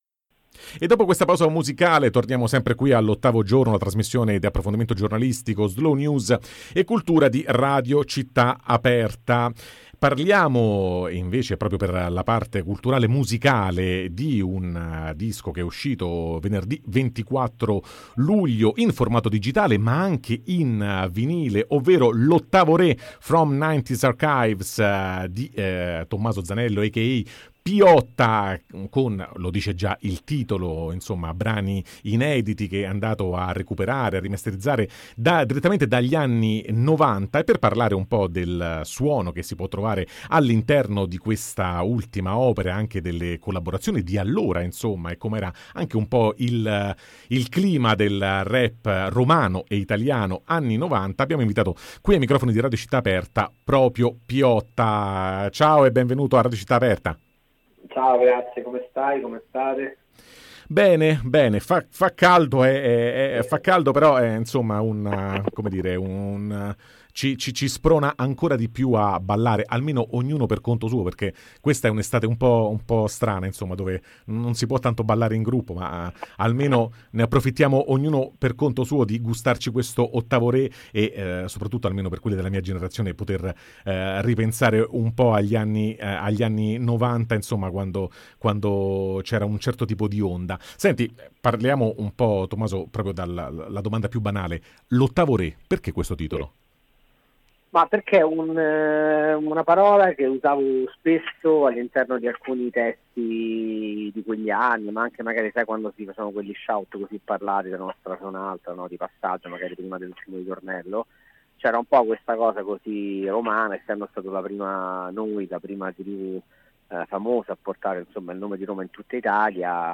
Tra rime, battaglie e fermenti culturali: con”L’Ottavo Re” Piotta apre i suoi archivi e ci porta in giro per una Roma bellissima [Intervista a Piotta] | Radio Città Aperta